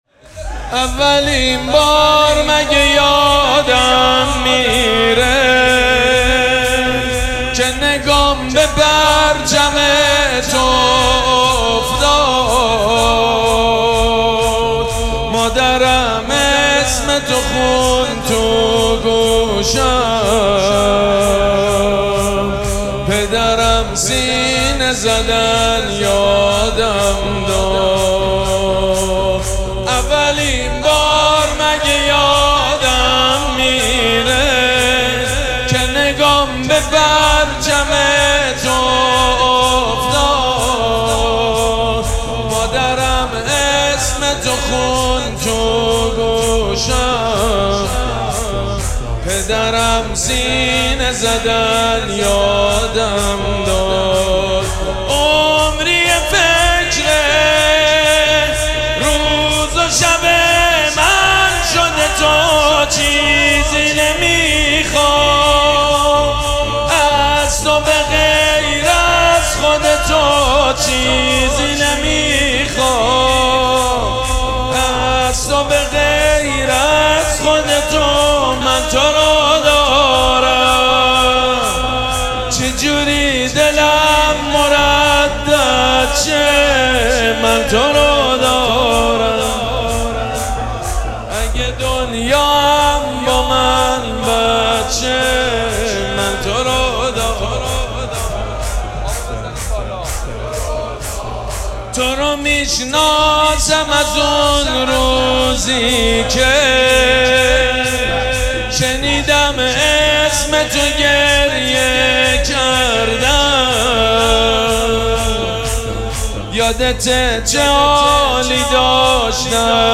مراسم عزاداری شهادت امام صادق علیه‌السّلام
شور
مداح
حاج سید مجید بنی فاطمه